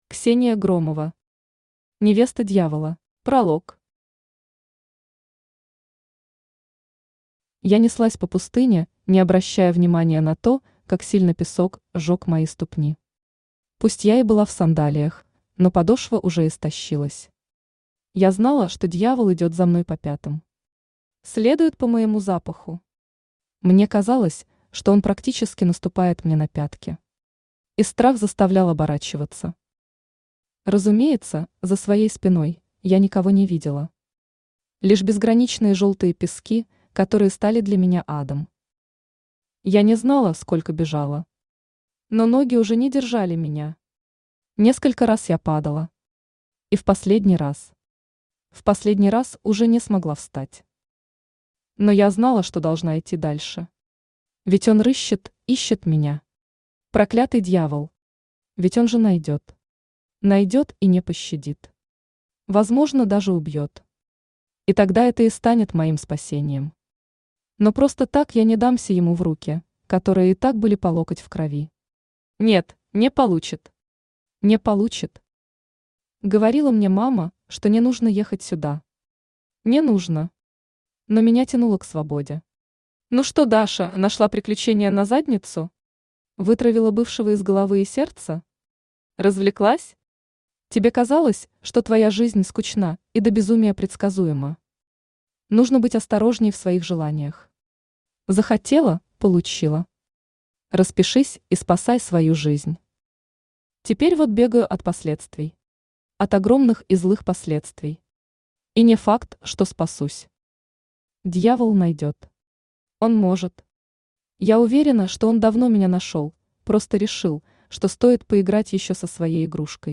Аудиокнига Невеста Дьявола | Библиотека аудиокниг
Aудиокнига Невеста Дьявола Автор Ксения Громова Читает аудиокнигу Авточтец ЛитРес.